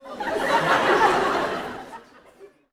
Audience Laughing-05.wav